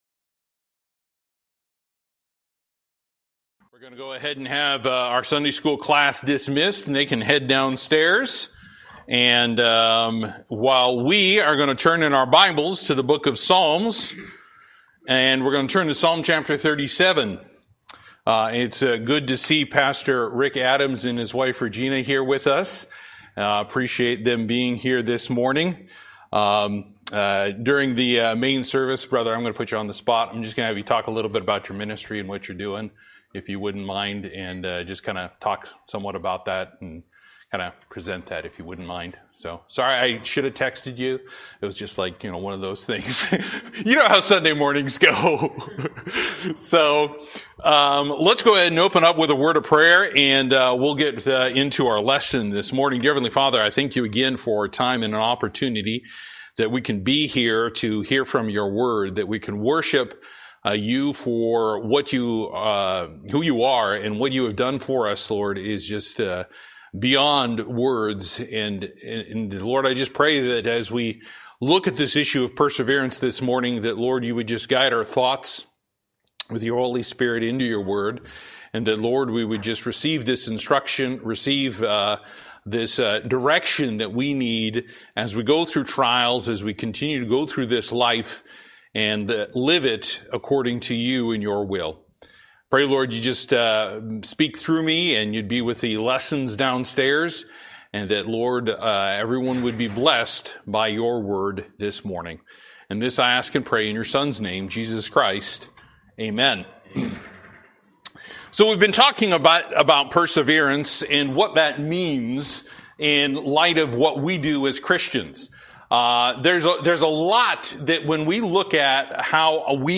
Service: Sunday School